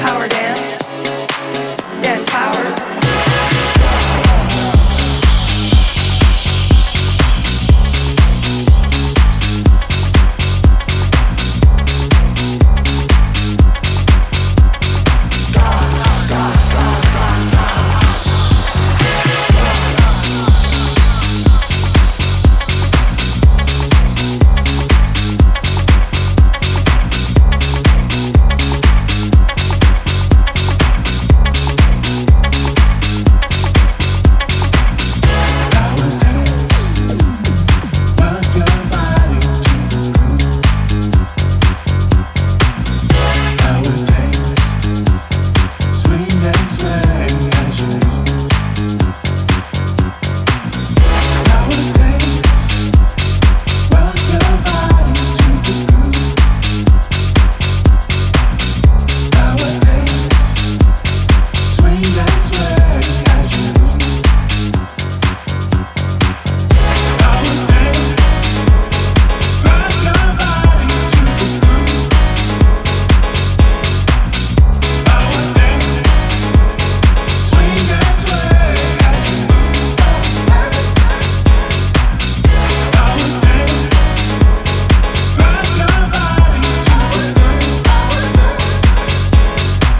ジャンル(スタイル) NU DISCO / DEEP HOUSE